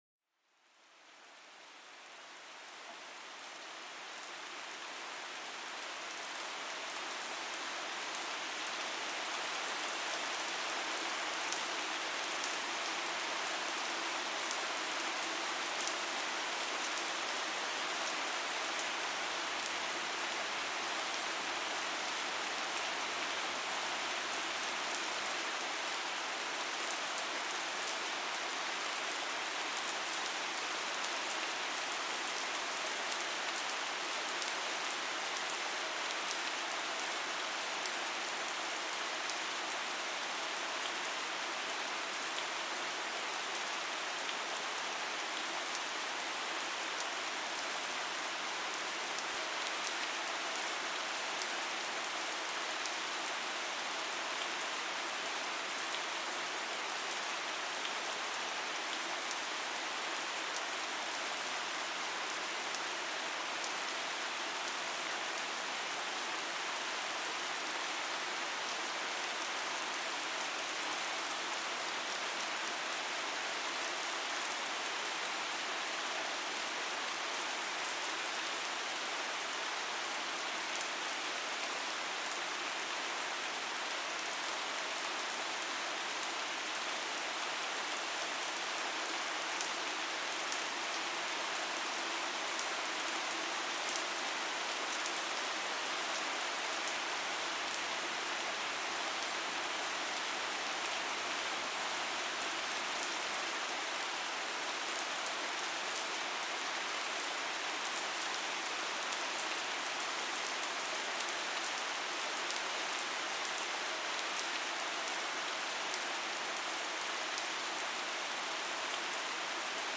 Pluie battante : un torrent de calme pour apaiser l'esprit